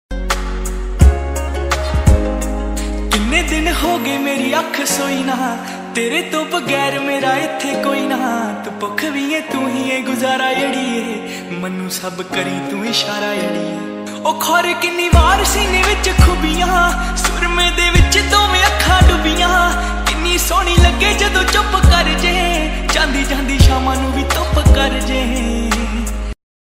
Soft emotional tone perfect for your mobile call ringtone.